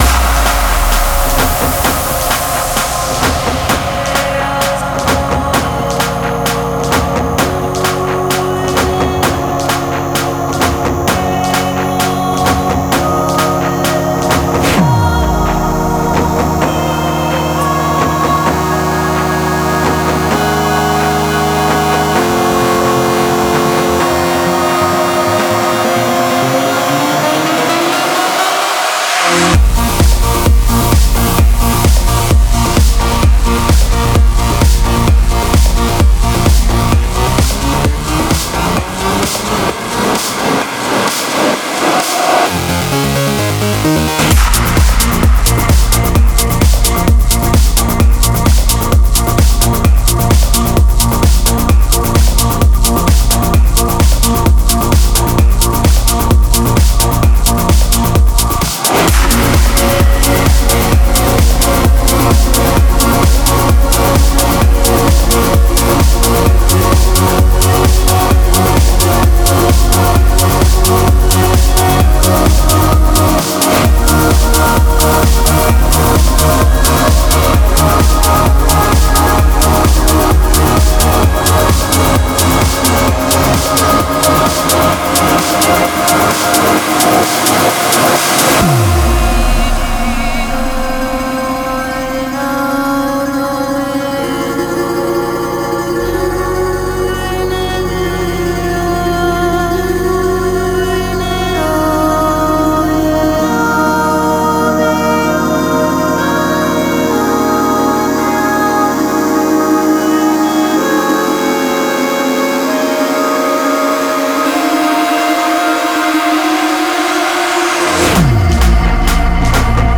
Vocal Trance